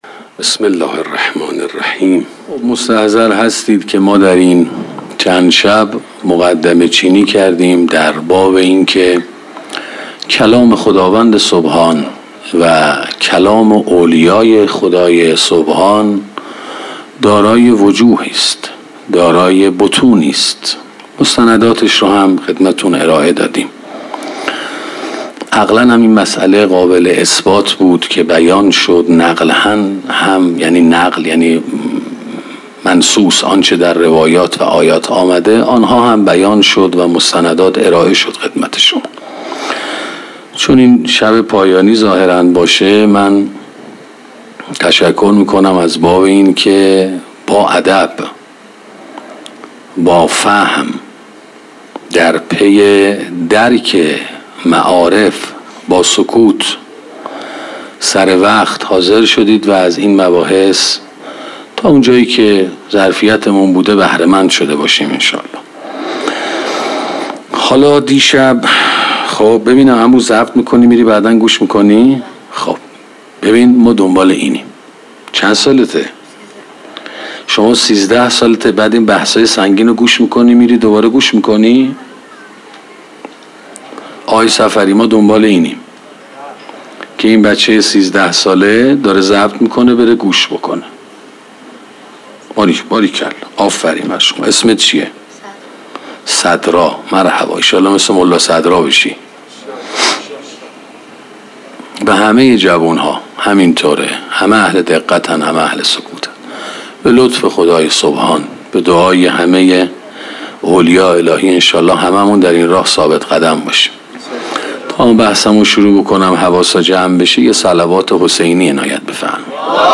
سخنرانی سلوک ثاراللهی 10